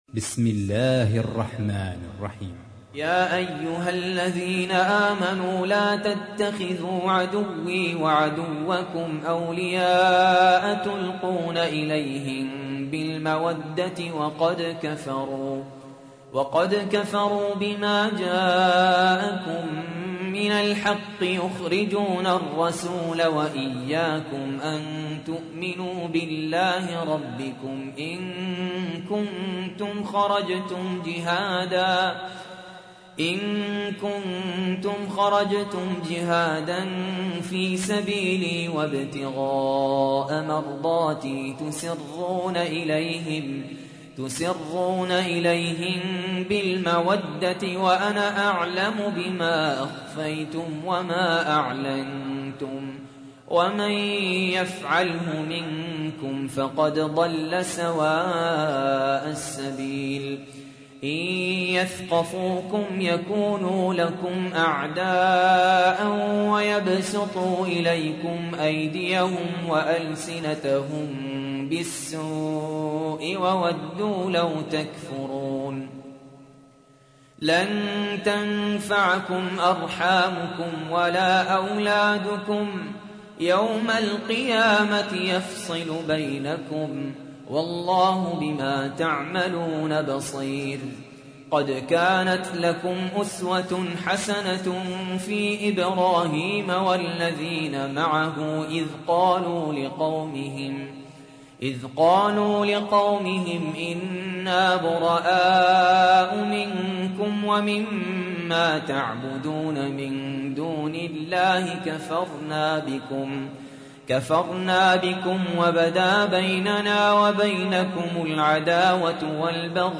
تحميل : 60. سورة الممتحنة / القارئ سهل ياسين / القرآن الكريم / موقع يا حسين